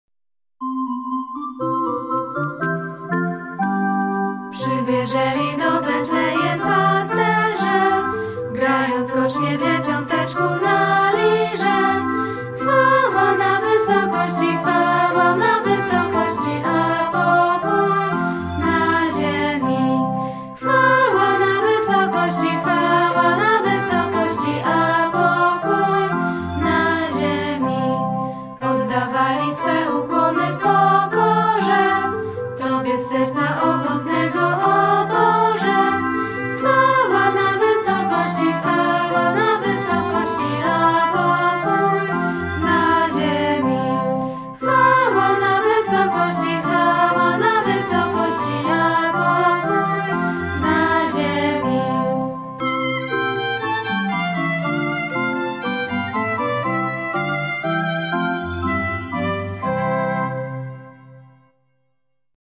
Kolędy:
Podsumowanie przedstawienia jasełkowego, które uczniowie naszego gimnazjum przygotowali z okazji Świąt Bożego Narodzenia 2010.